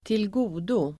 Ladda ner uttalet
Uttal: [²tilg'o:do]